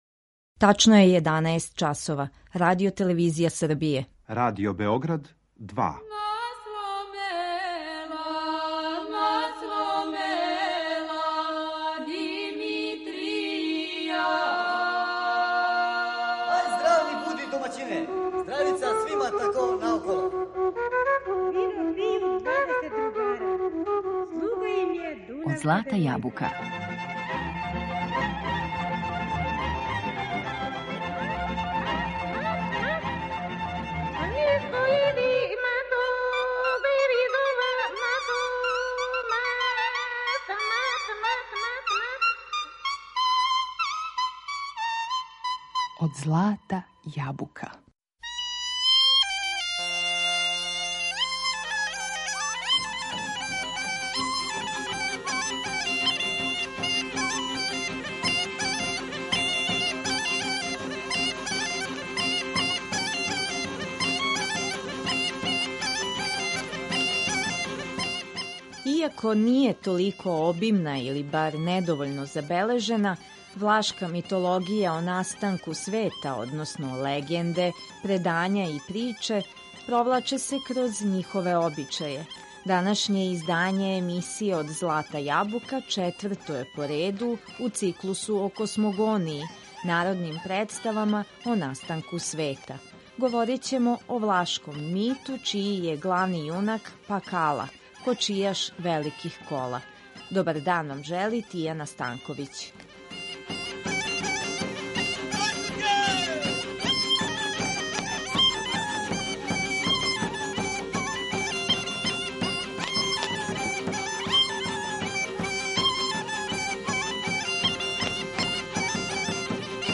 На репертоару су најлепша извођења народних мелодија на фестивалу „Црноречје у песми и игри".